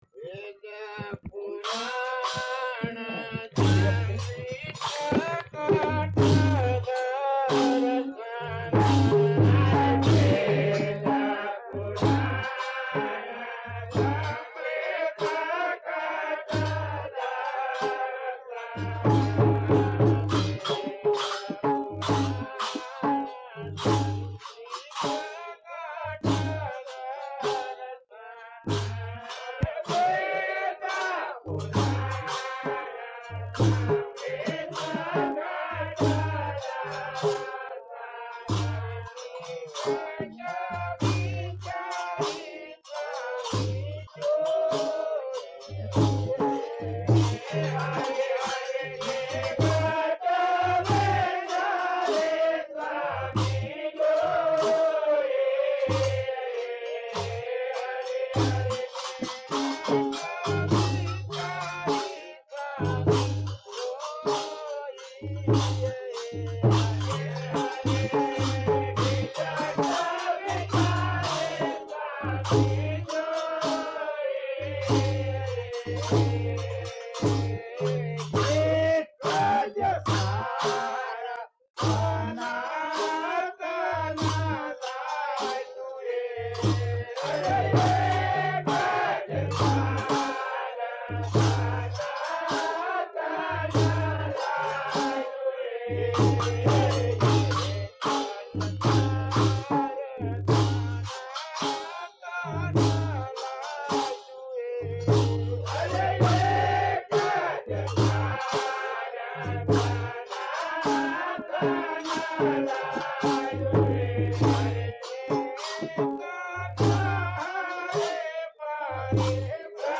traditional bhajans